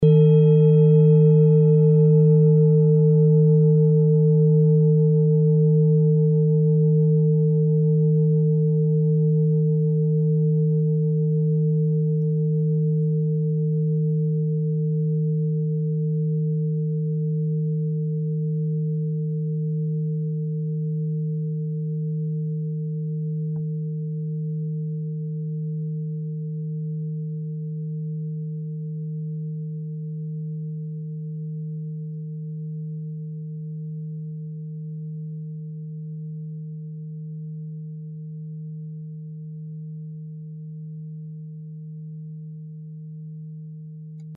Klangschale TIBET Nr.26
Klangschale-Durchmesser: 22,0cm
Sie ist neu und ist gezielt nach altem 7-Metalle-Rezept in Handarbeit gezogen und gehämmert worden.
(Ermittelt mit dem Filzklöppel oder Gummikernschlegel)
klangschale-tibet-26.mp3